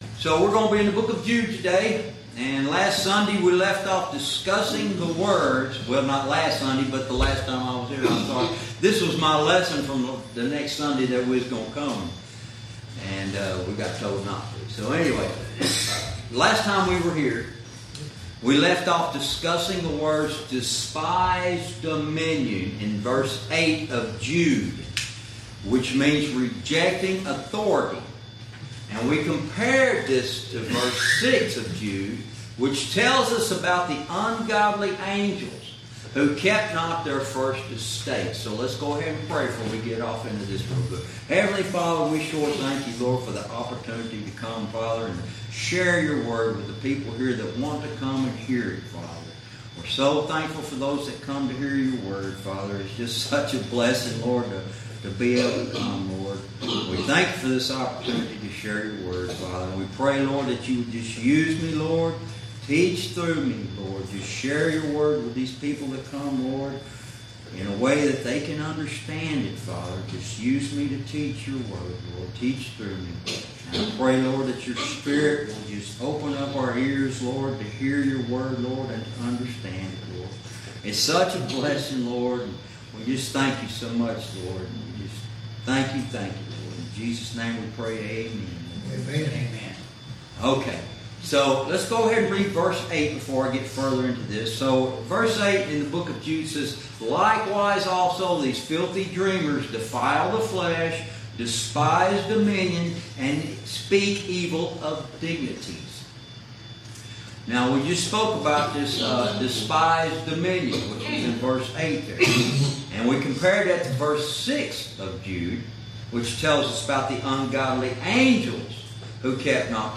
Verse by verse teaching - Lesson 28